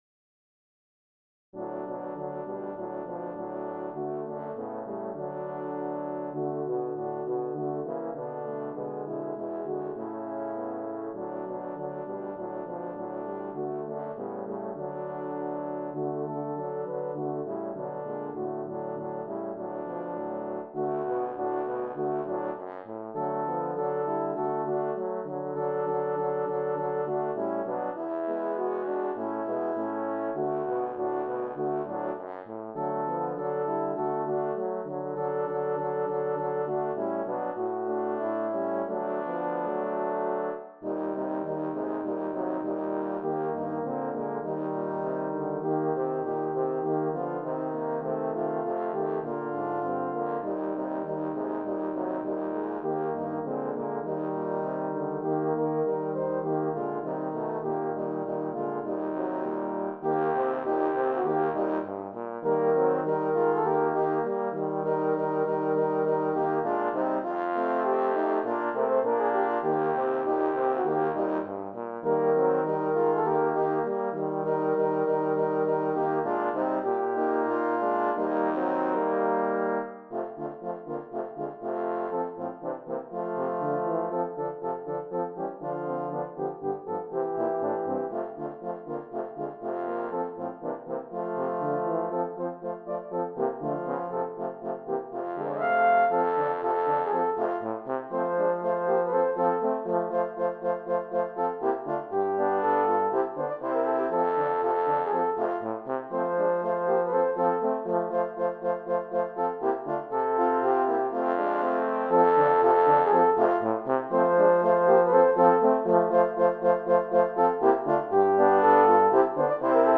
HYMN MUSIC; BLUEGRASS GOSPEL MUSIC